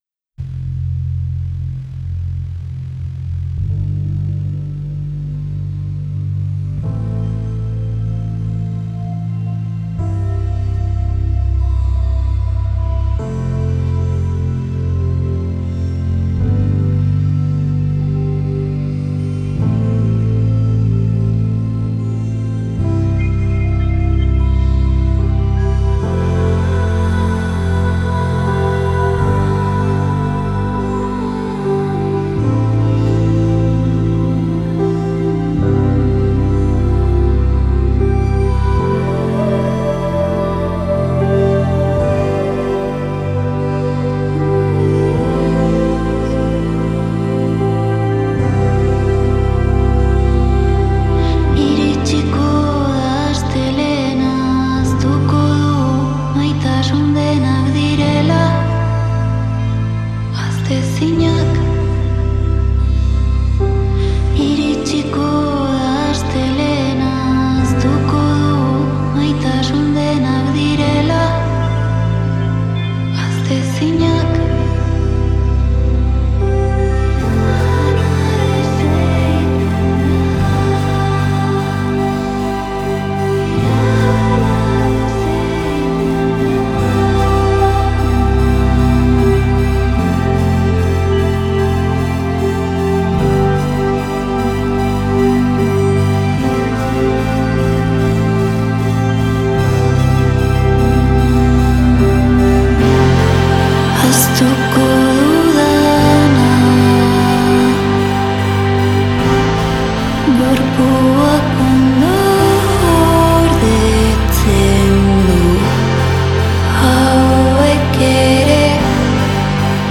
Genres: Experimental, Dreamgaze, Ambient